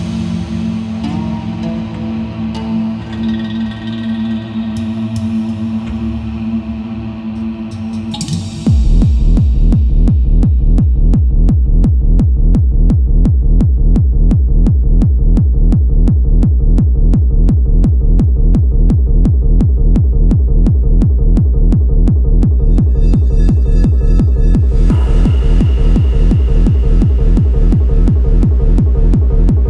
疾走172BPM金縛り系トランスグルーヴ